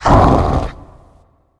spawners_mobs_balrog_hit.2.ogg